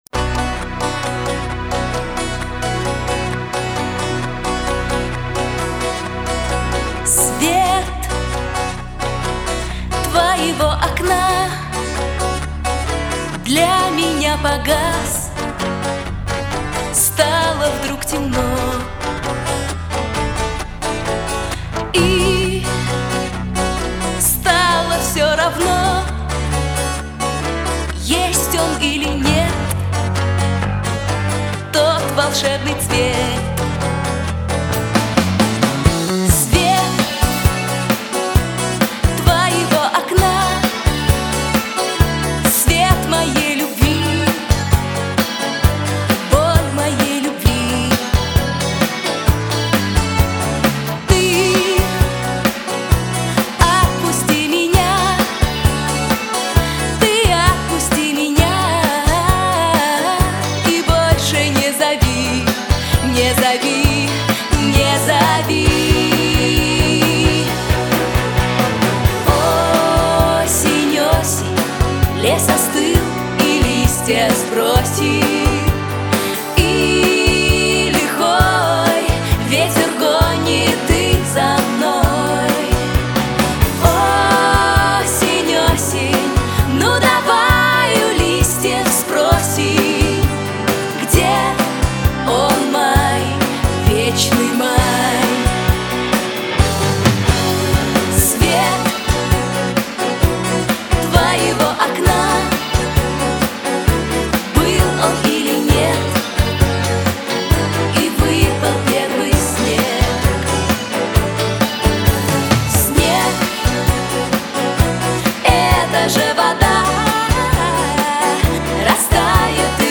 Из старых времён дошла эта скамейка:-))....И Музыка!...Мужской хор этого монастыря...очень нравится:-))